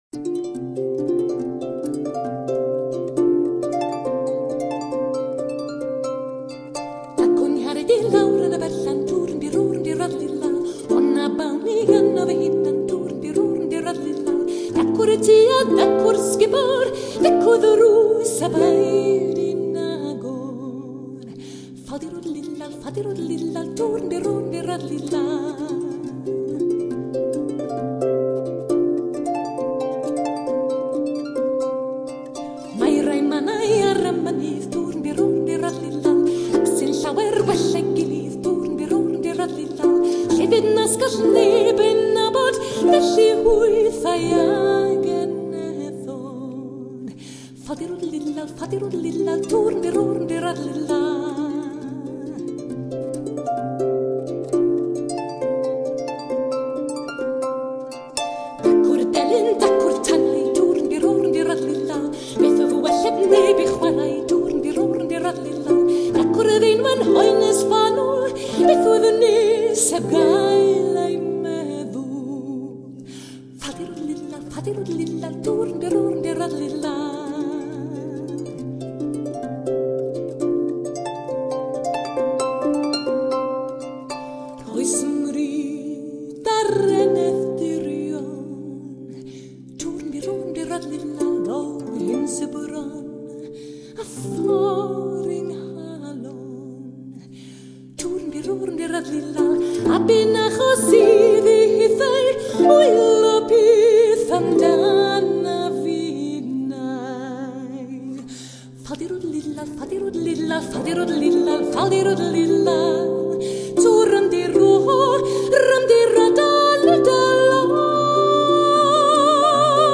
Harp- Sizes vary from large pedal to small hand held.
I have listened to a piece of Welsh harp music called Dacw 'Nghariad and this piece of music made me smile and I wanted to sing along but the words are in Welsh.  The instrument is a Celtic harp with vocal accompaniment.